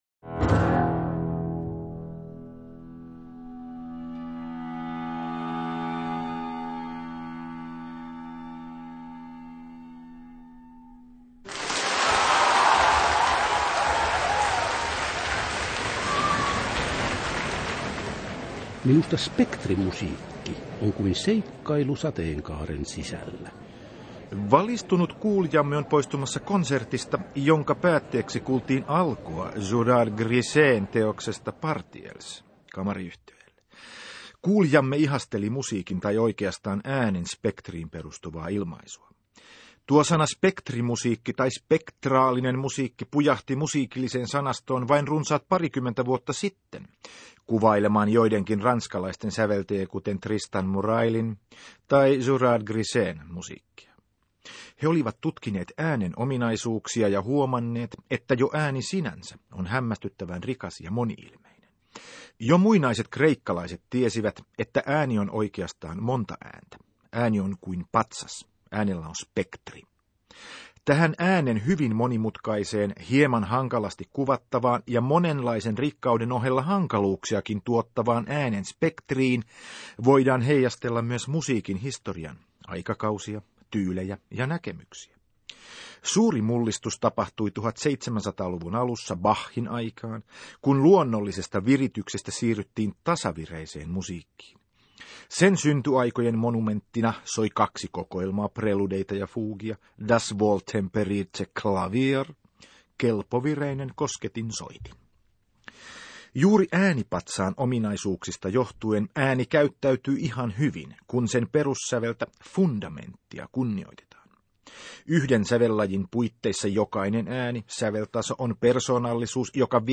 Julkaistu ensi kerran 14.12.2001; Radio Ylen Ykkösen musiikkiohjelmat